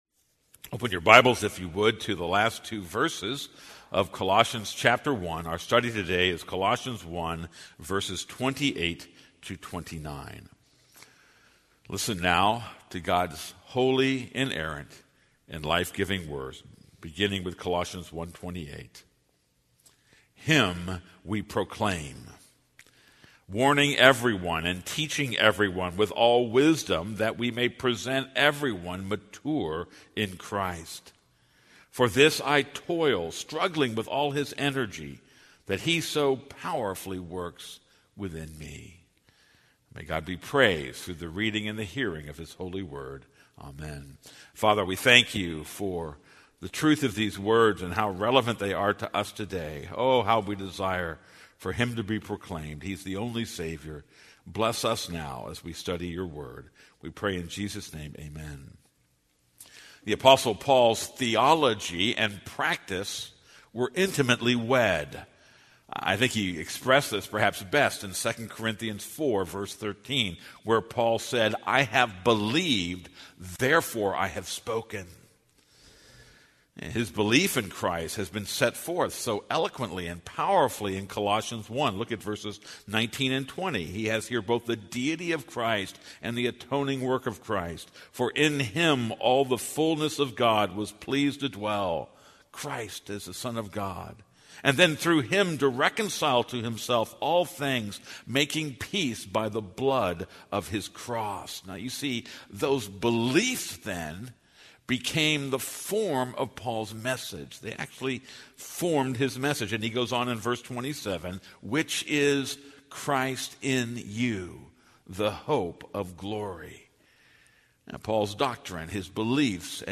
This is a sermon on Colossians 1:28-29.